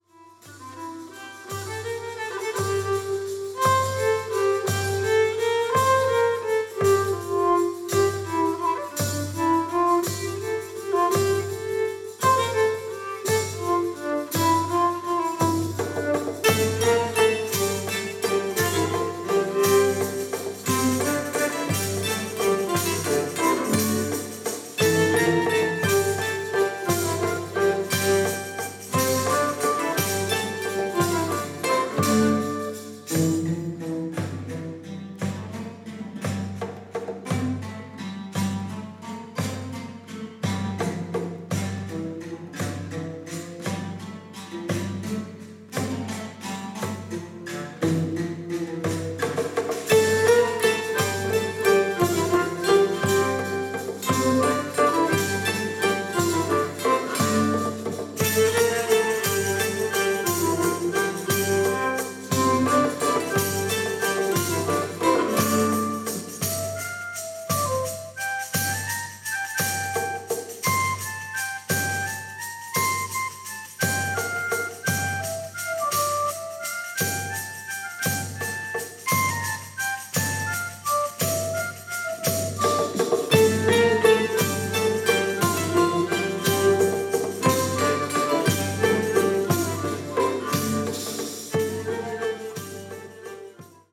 Title : Atrium Musicae De Madrid
中世の詩人・ティボーの旋律を素材にした幻想絵巻。